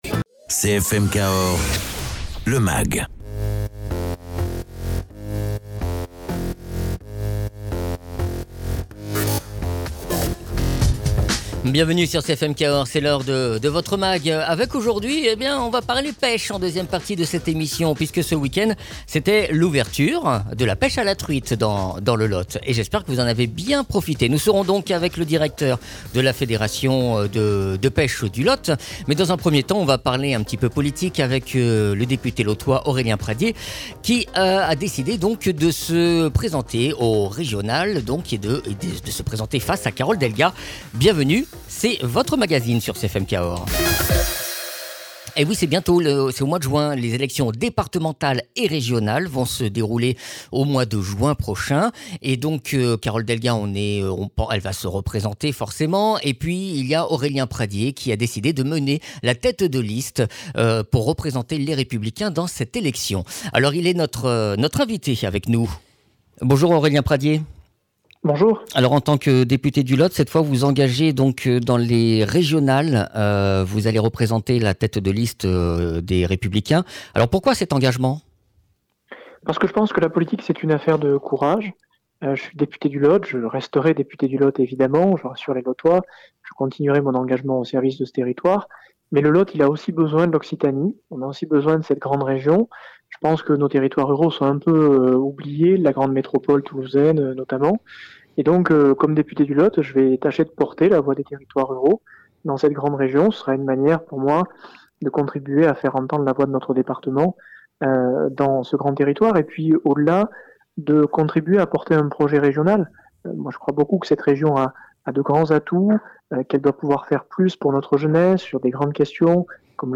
Invité(s) : Aurélien Pradié, député LR du Lot